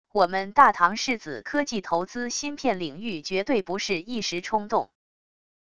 我们大唐世子科技投资芯片领域绝对不是一时冲动wav音频生成系统WAV Audio Player